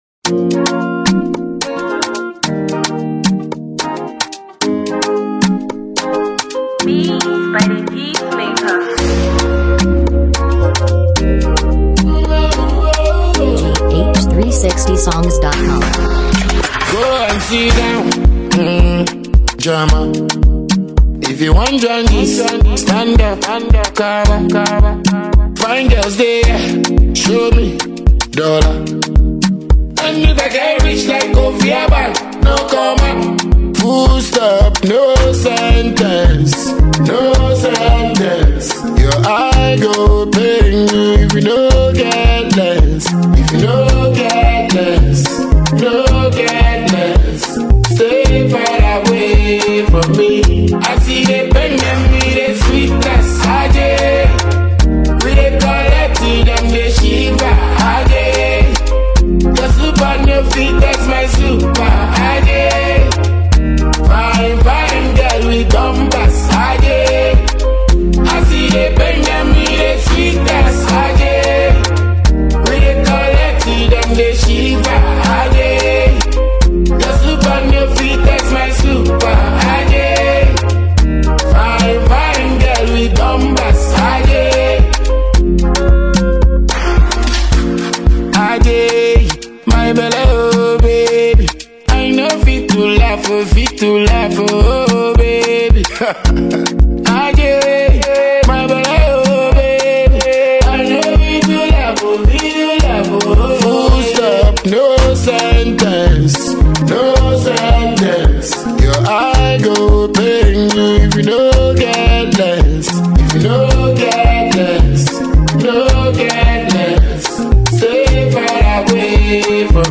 Dancehall artist
one-of-a-kind dancehall-afrobeats track
captivating, engaging vocal delivery